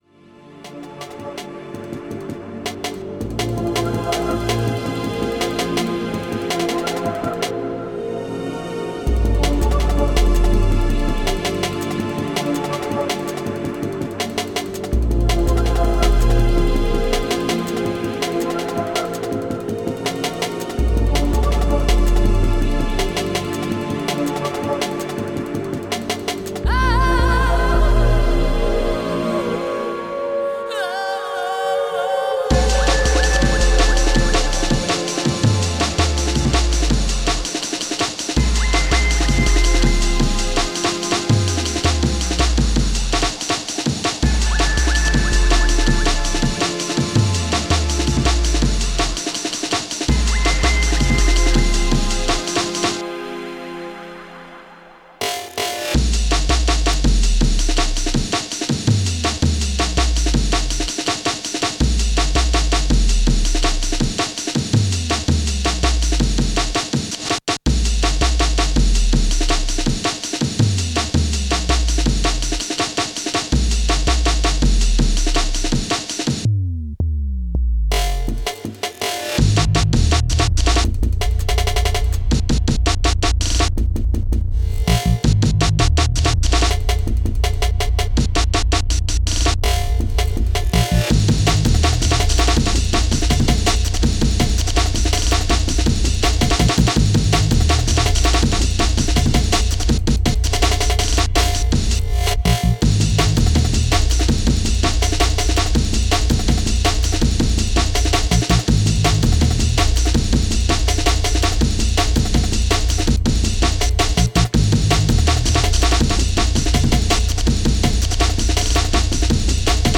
professionally remastered